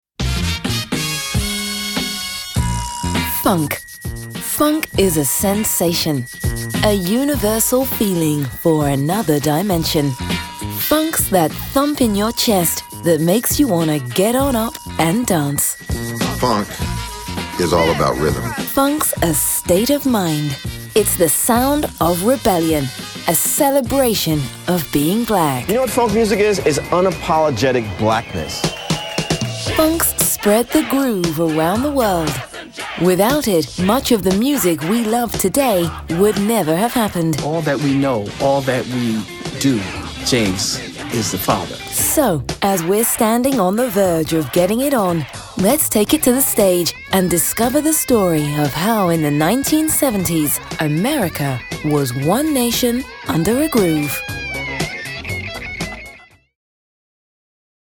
Female
English (British)
Adult (30-50)
Documentary